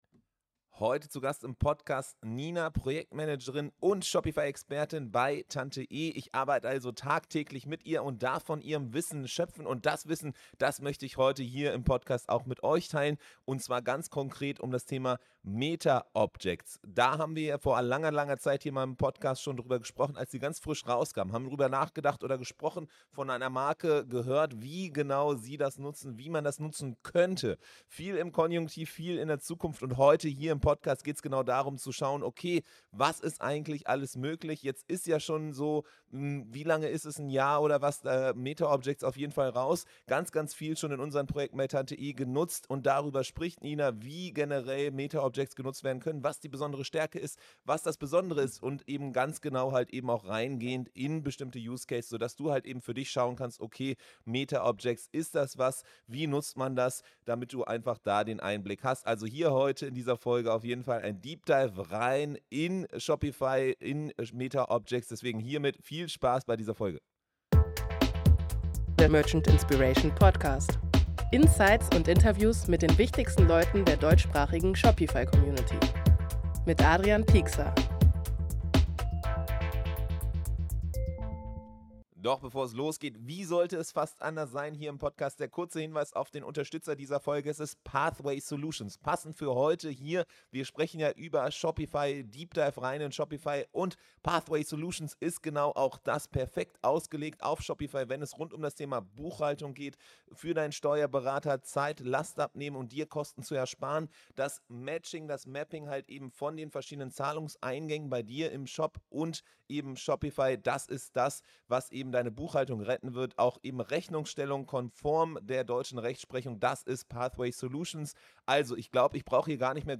In dieser Folge ist daher eine absolute Shopify Expertin zu Gast, die tagtäglich mit Shopify und entsprechend auch mit Metaobjekten arbeitet.